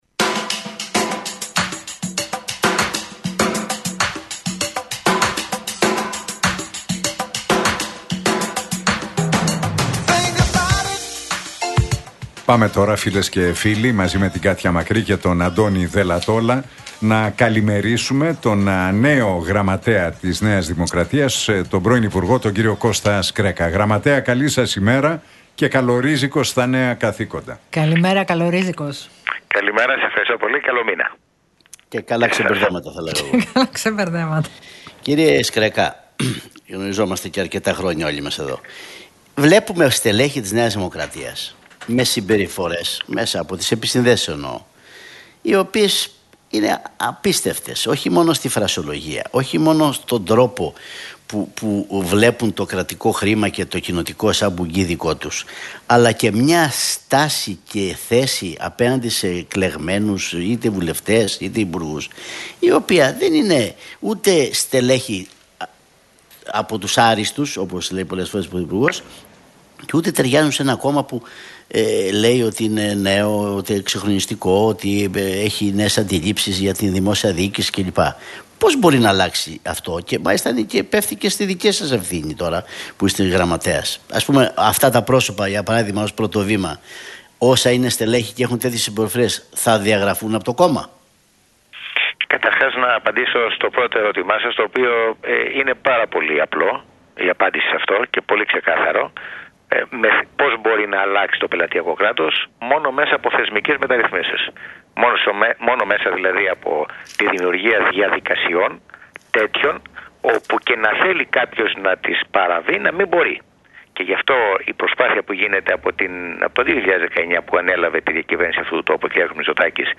Σκρέκας στον Realfm 97,8 για ΟΠΕΚΕΠΕ: Έγινε μία προσπάθεια που προφανώς δεν ήταν αποτελεσματική και το παραδέχτηκε ο Πρωθυπουργός